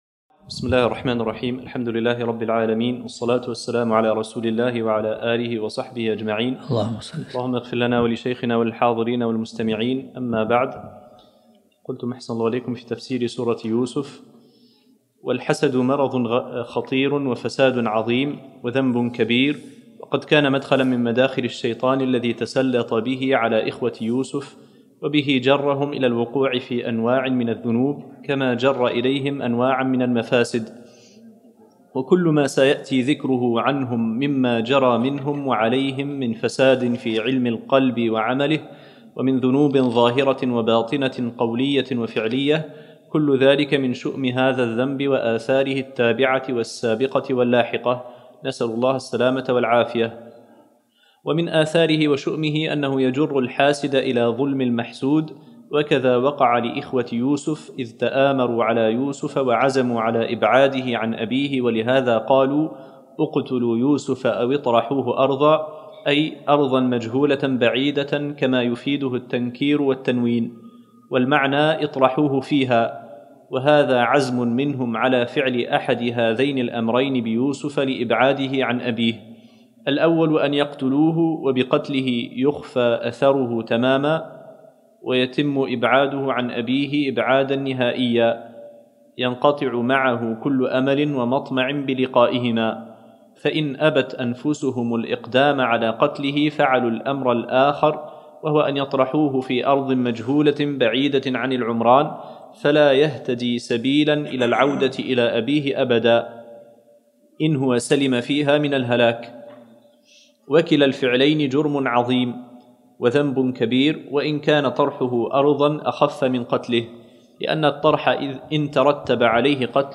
الدرس الثالث من سورة يوسف